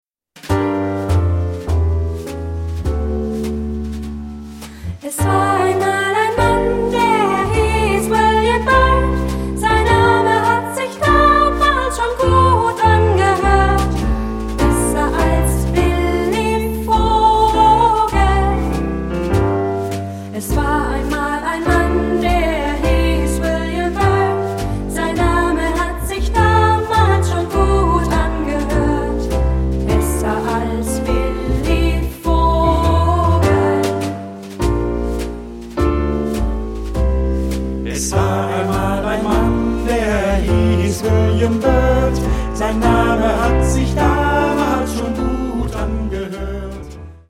Partsong. Canon. Choral jazz.
Tonality: G minor